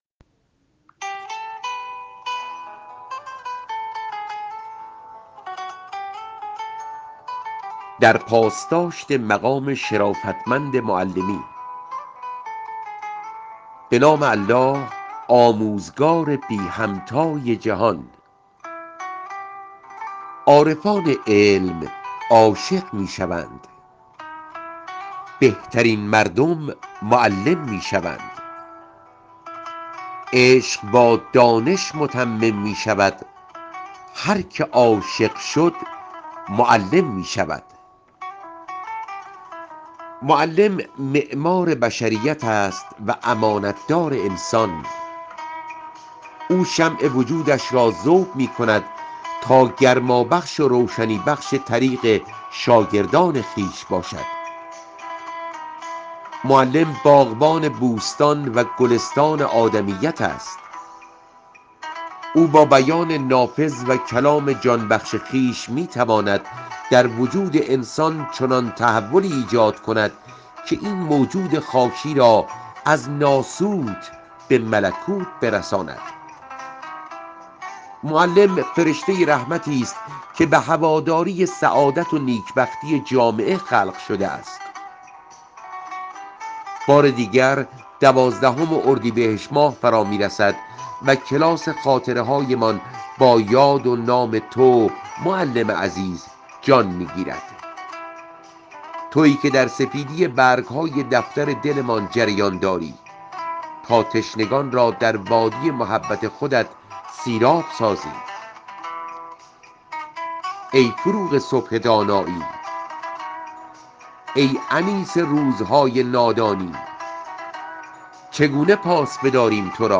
خوانش متنی را درباره این موضوع در اختیار ایکنا قرار داده است که می‌شنویم